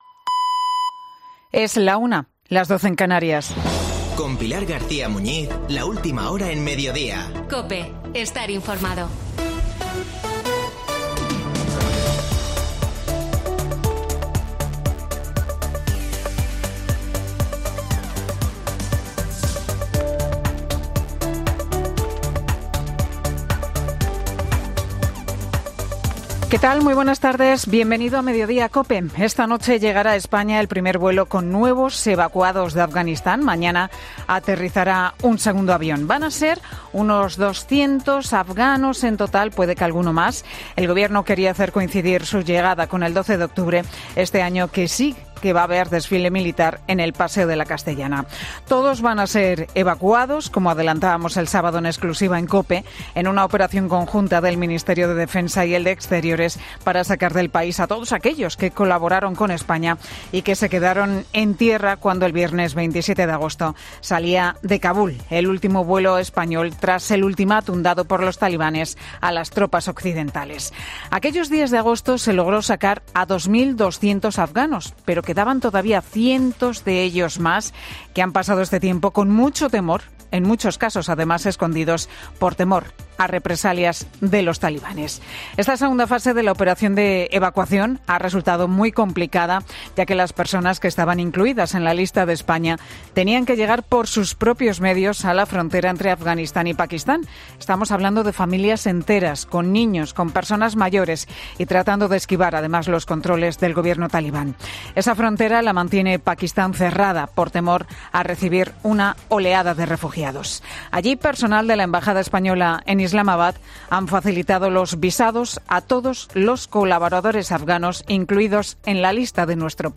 Boletín de noticias COPE del 11 de octubre 2021 a las 13:00 horas